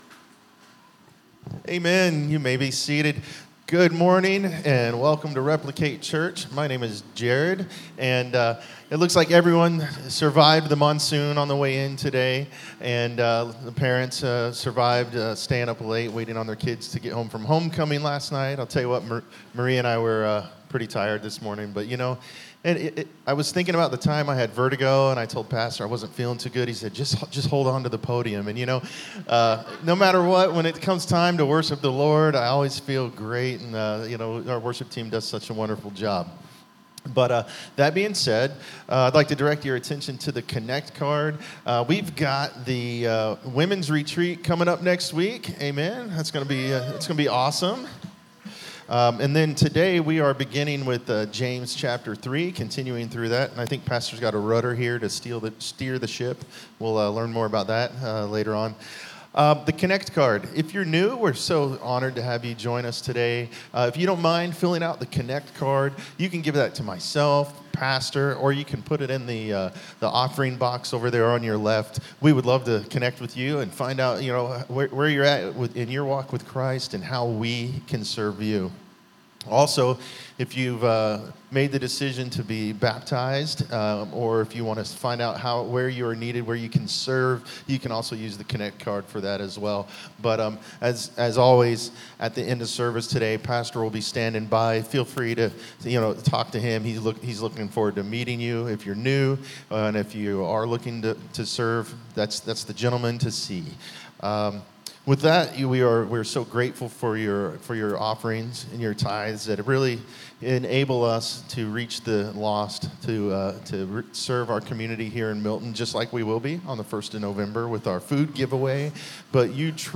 Sermons | Replicate Church